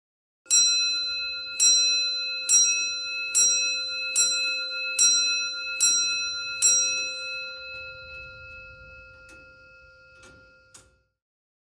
22. Звук настенных старинных часов, которые бьют время (бой времени восемь часов)
chasy-biut-vremia-1.mp3